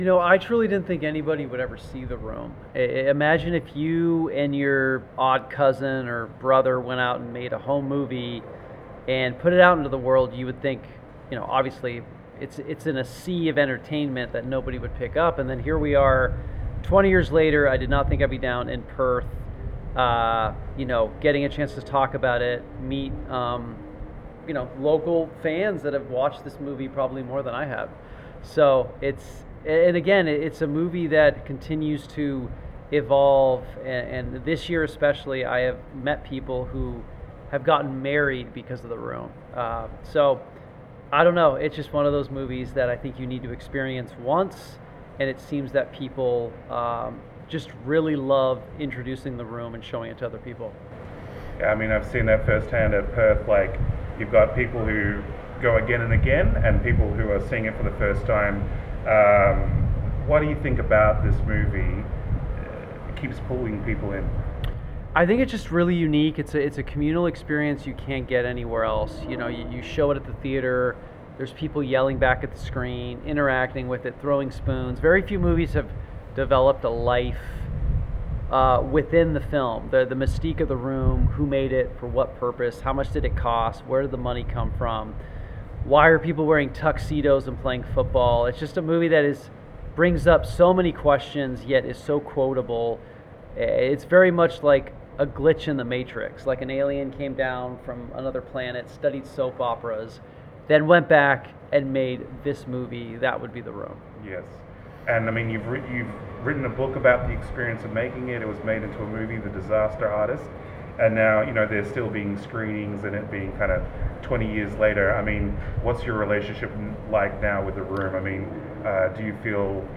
Greg-Sestero-The-Room-and-Miracle-Valley-2023-Interview.mp3